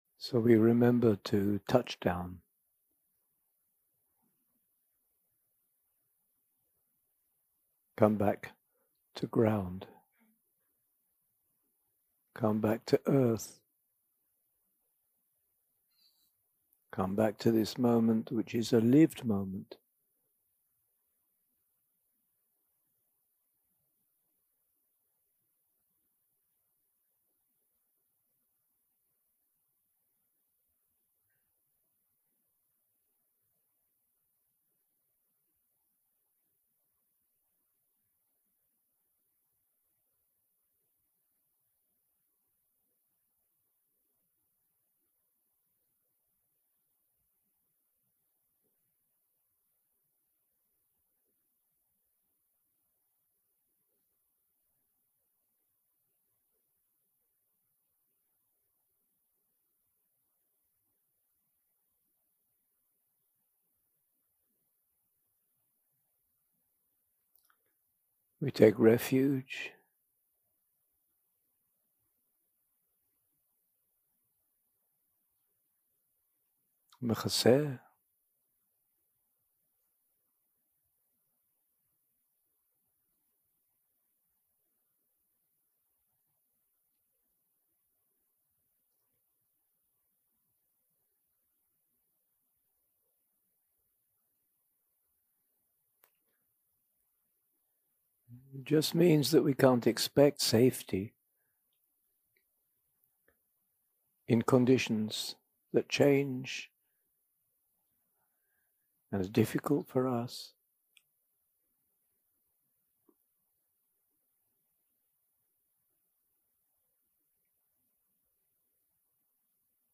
יום 6 – הקלטה 15 – צהריים – מדיטציה מונחית
Dharma type: Guided meditation שפת ההקלטה